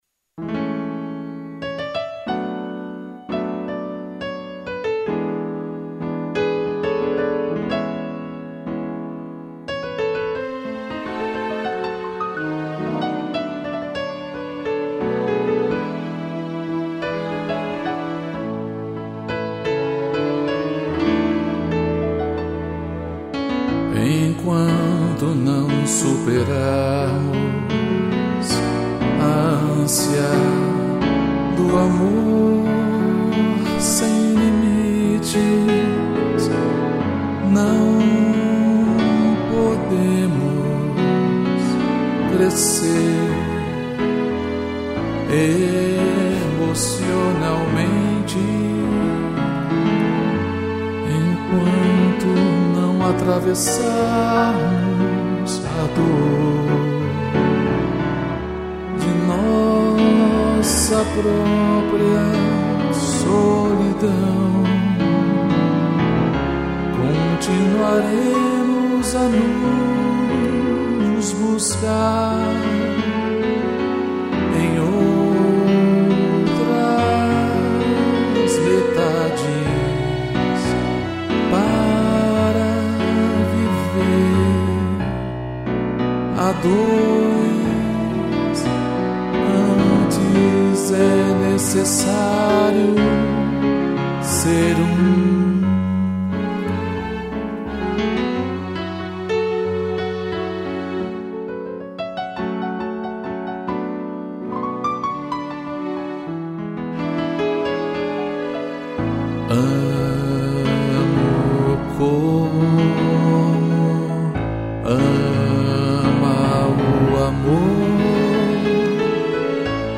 piano, cello e strings